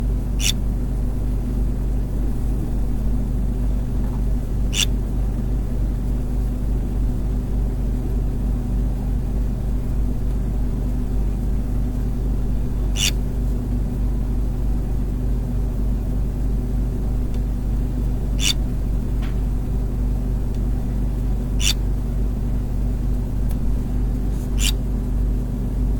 Voici donc les bruits isolés et amplifiés.
Bruits grésillement tableau électrique
Il y a un bruit de fond autour de 61 Hz, auquel se superpose de façon non périodique le signal complexe d'origine inconnue.
C'est exactement le même bruit : court, aigu au début, qui se fait légèrement plus grave.
bruits-tableau-amp-cut.mp3